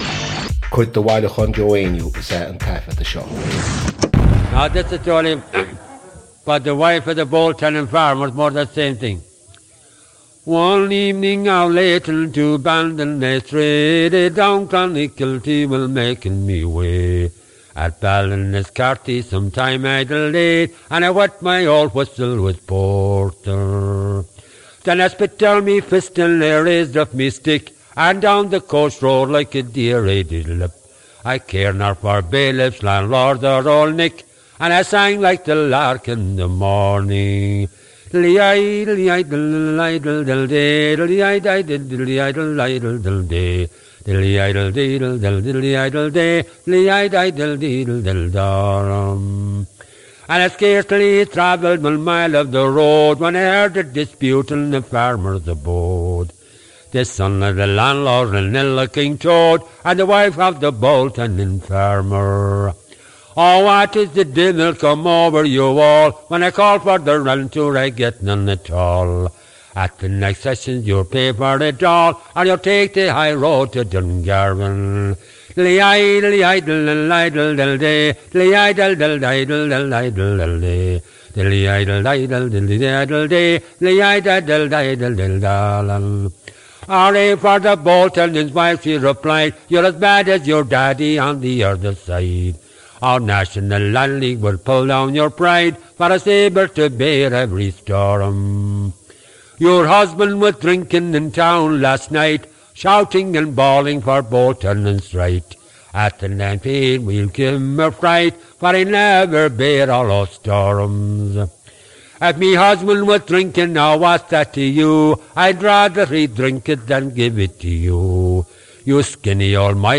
• Catagóir (Category): song.
• Ainm an té a thug (Name of Informant): Joe Heaney.
• Suíomh an taifeadta (Recording Location): University of Washington, United States of America.
• Ocáid an taifeadta (Recording Occasion): day class.
1. Joe actually says ‘At the nampaign’ – presumably thinking for a split second of the third line in the fourth verse, which begins ‘At the next Sessions,’ before correcting himself.